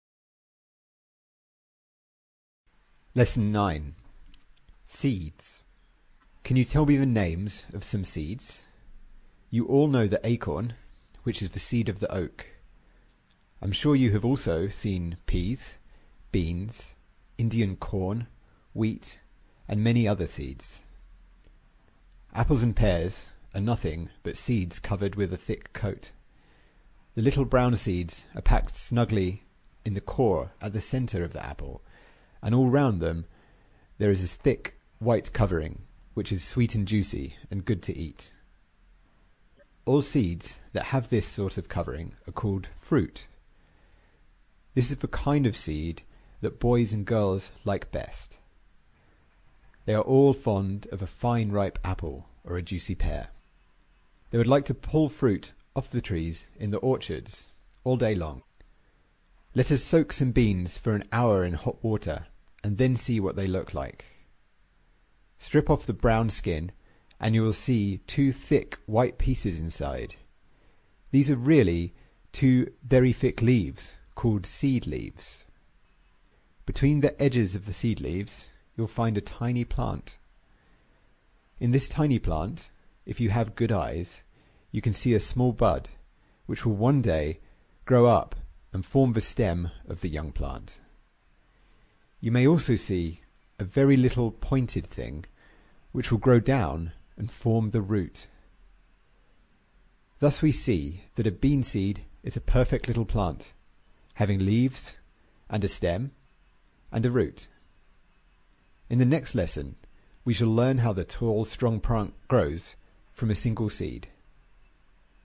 在线英语听力室英国学生科学读本 第9期:种子(1)的听力文件下载,《英国学生科学读本》讲述大自然中的动物、植物等广博的科学知识，犹如一部万物简史。在线英语听力室提供配套英文朗读与双语字幕，帮助读者全面提升英语阅读水平。